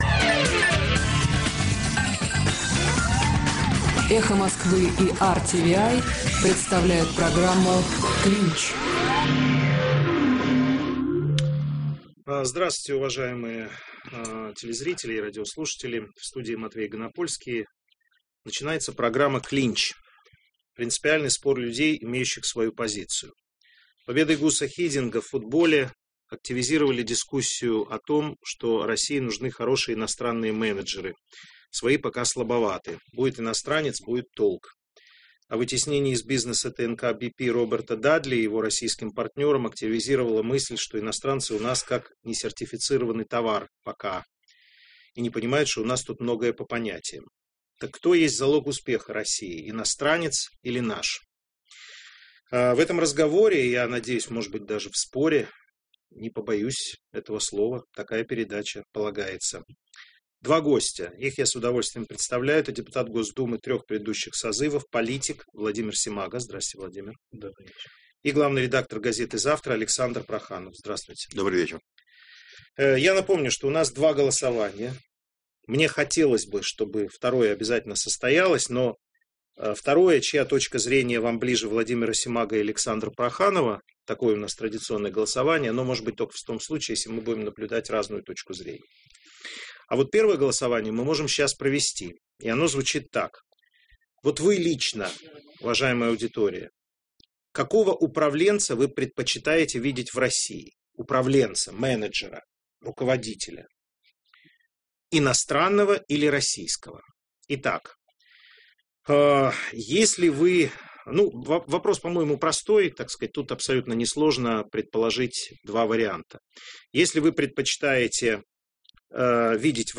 В эфире - «Клинч» - принципиальный спор людей, имеющих свою позицию. В студии - Матвей Ганапольский.